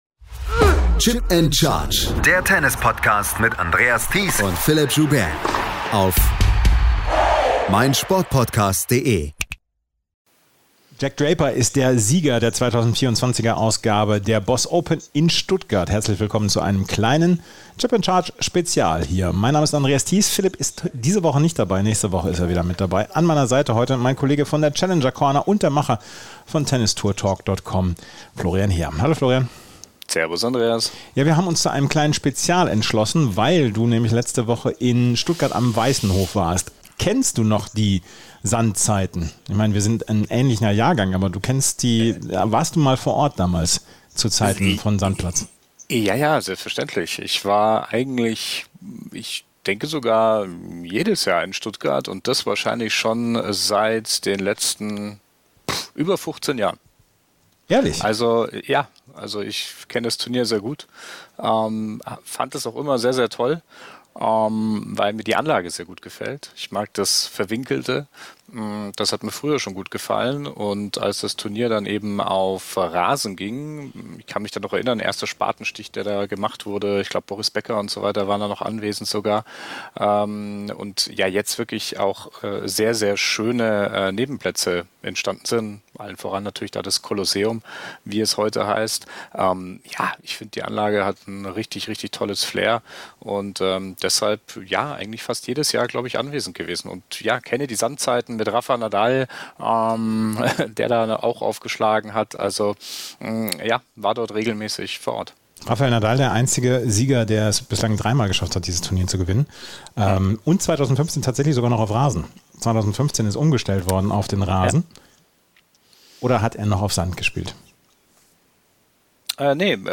Jack Draper gewinnt die BOSS Open - Michael Berrer im Interview ~ Tennis Podcast
jack-draper-gewinnt-die-boss-open-michael-berrer-im-interview.mp3